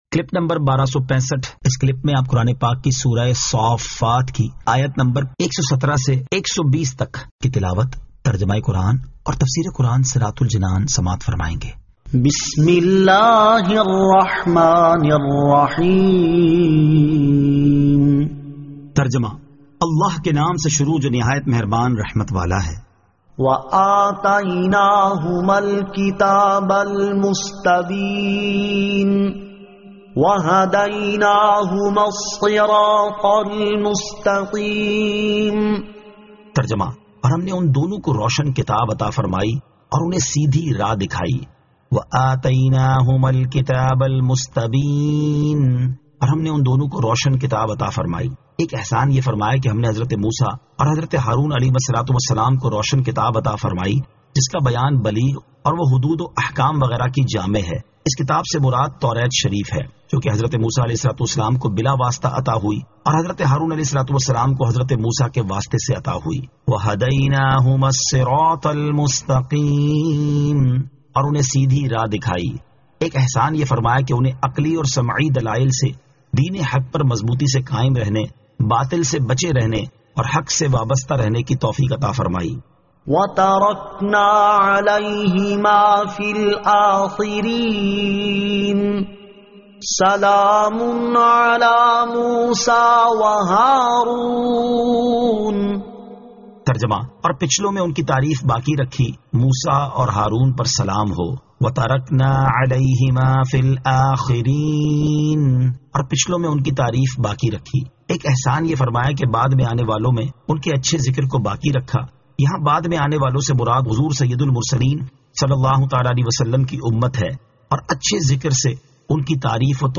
Surah As-Saaffat 117 To 120 Tilawat , Tarjama , Tafseer